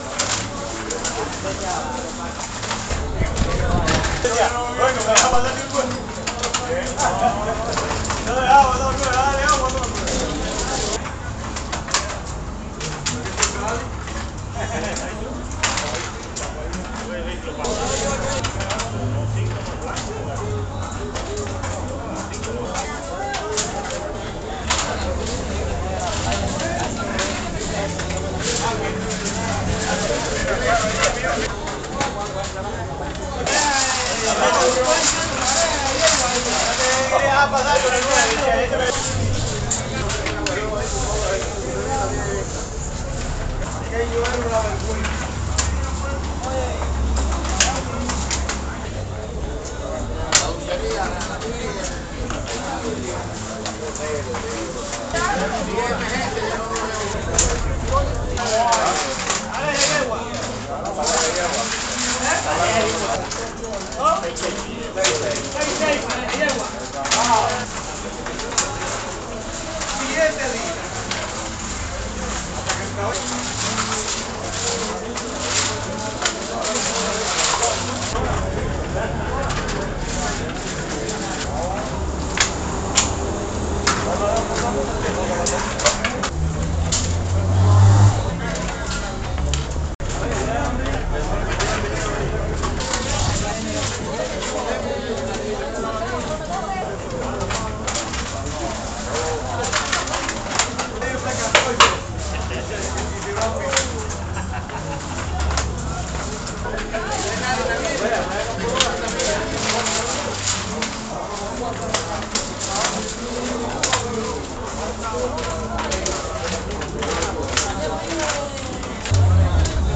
A description of how you applied the Dada techniques to the sound to produce your piece: I chopped the file into pieces and numbered them. The samples became progressively shorter.
I was left with 74 samples varying from 1 - 7 seconds.
I blindly drew each number out and reassembled the track in that order.